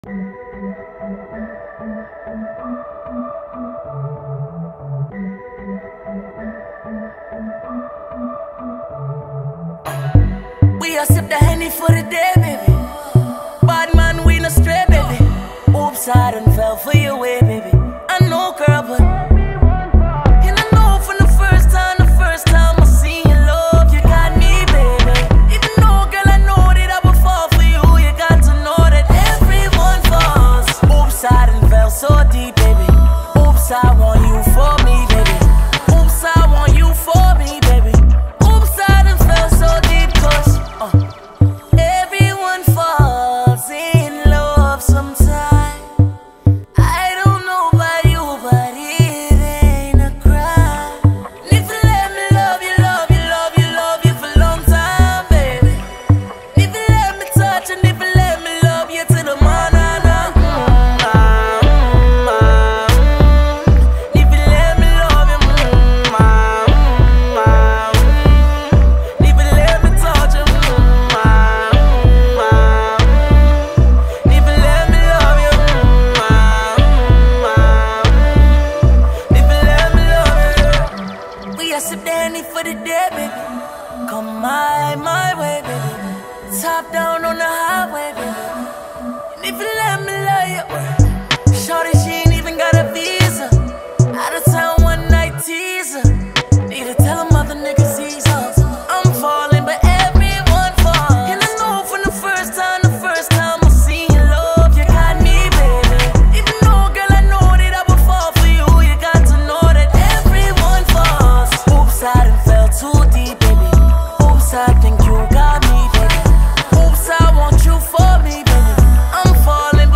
вплетенное в ритмичный и мелодичный хип-хоп.